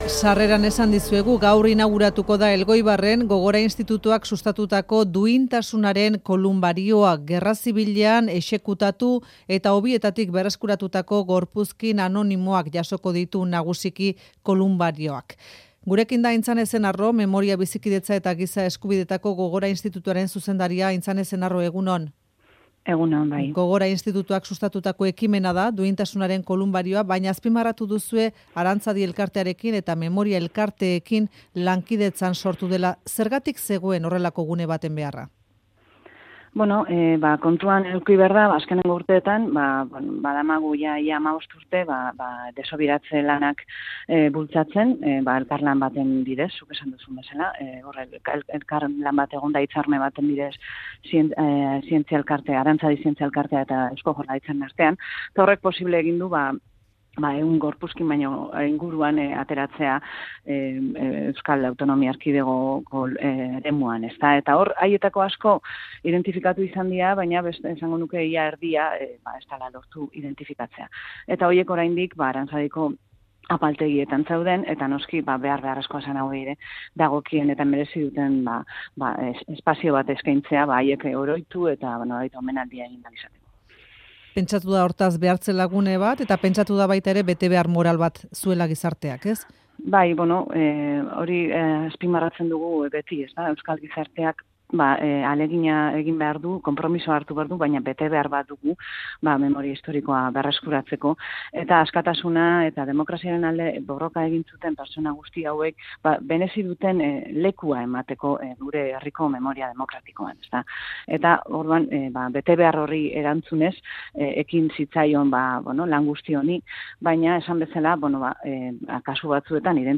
Aintzane Ezenarro Gogora Institutoaren zuzendaria Faktorian.